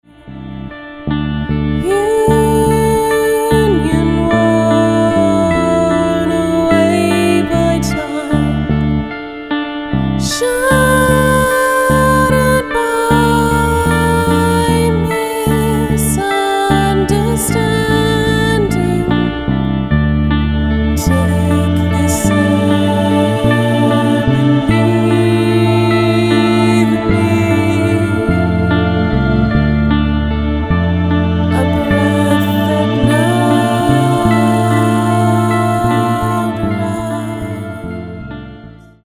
gothic/darkwave duo